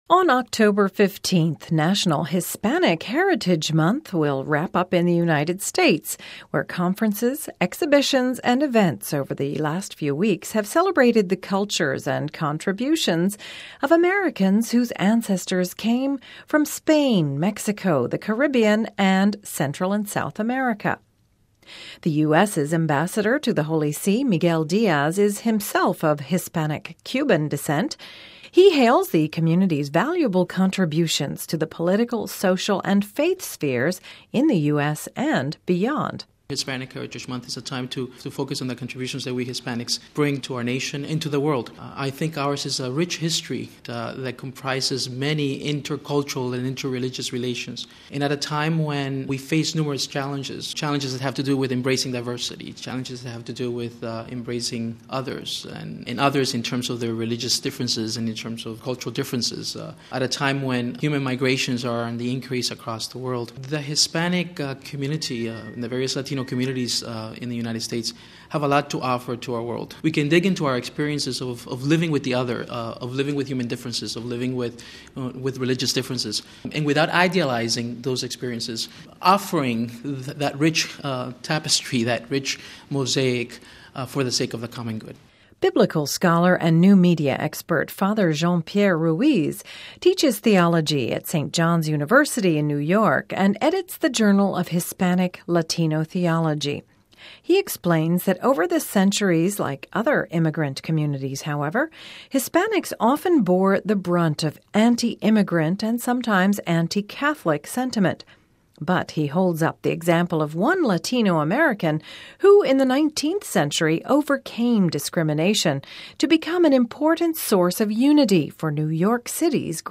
On October 15 th , National Hispanic Heritage Month will wrap up in the United States where conferences, exhibitions and events over the last few weeks have celebrated the cultures and contributions of Americans whose ancestors came from Spain, Mexico, the Caribbean and Central and South America. We hear from the U.S.’s ambassador to the Holy See, Miguel Diaz, himself of Hispanic Cuban descent, who hails the community’s valuable contributions to the political, social and faith spheres in the U.S. and beyond.